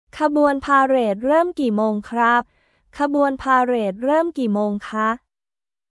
カブアン パーレート ルーム ギー モーン カップ/カー